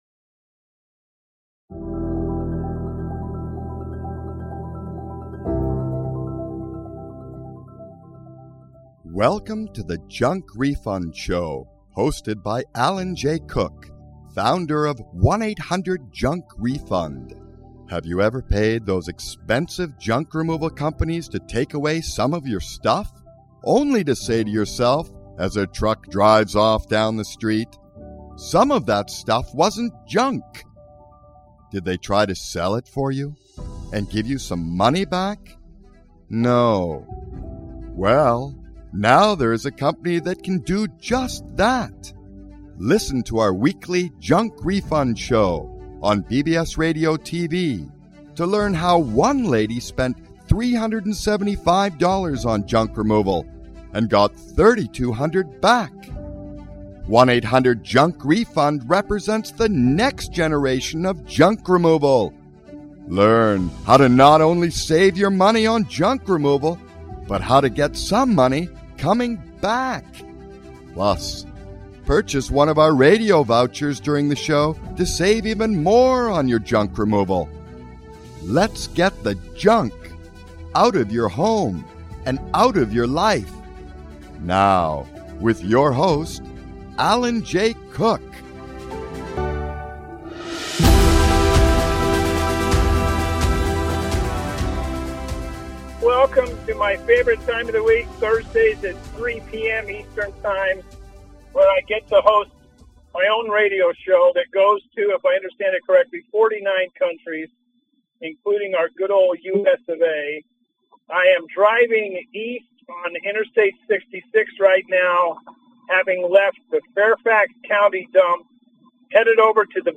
On the road. Left Fairfax County Dump and heading North to Bethesda, Maryland